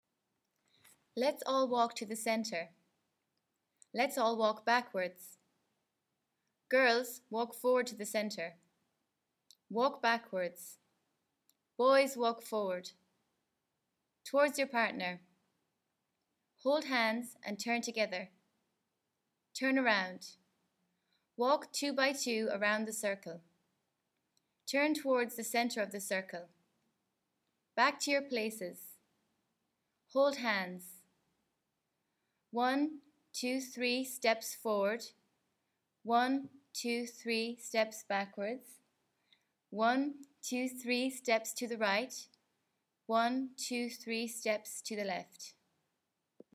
Le script bilingue des consignes pour diriger une danse traditionnelle collective (cercle circassien, ici : The Irish Washerwoman) est accompagné de 2 fichiers MP3 (enregistrement par une voix irlandaise, enregistrement par une voix américaine) et de 2 partitions (dont une avec tablatures).